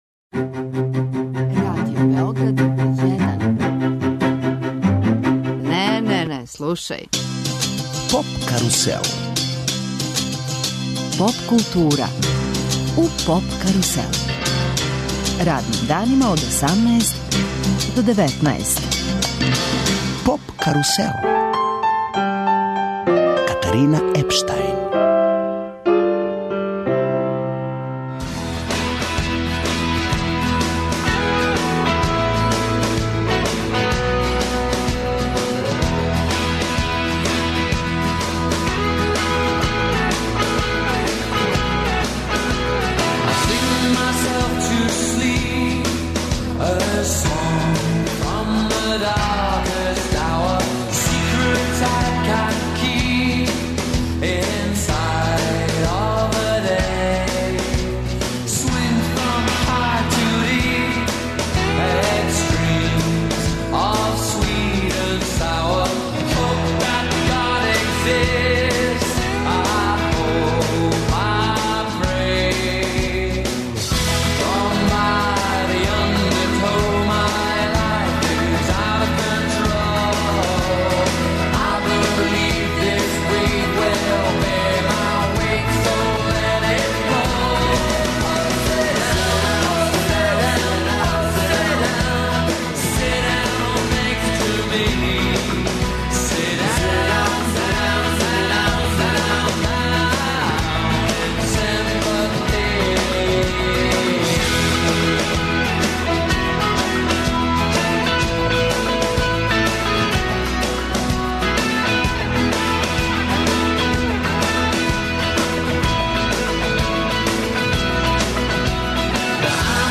У емисији можете чути и интервју са Влатком Стефановским.